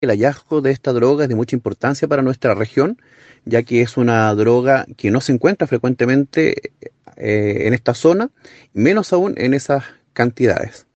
El fiscal Gonzalo Valderas calificó el decomiso como muy importante.